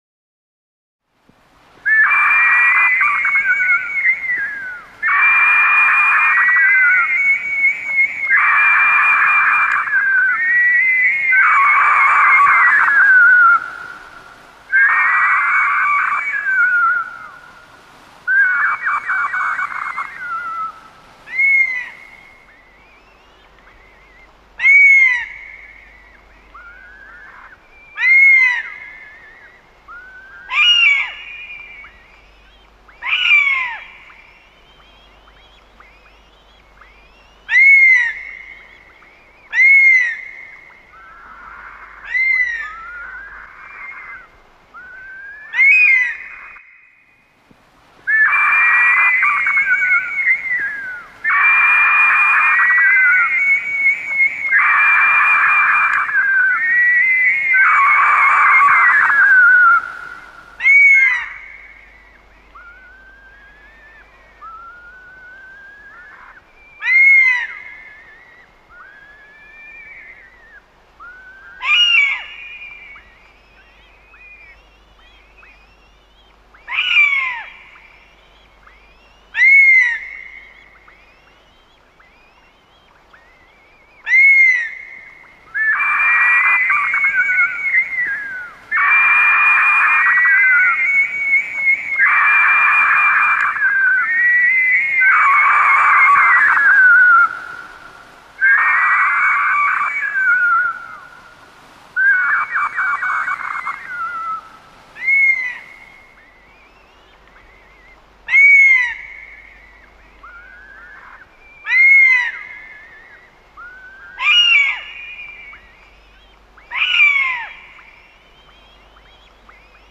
6Use Sounds of Birds of Prey
birds-of-prey-sound-effects.mp3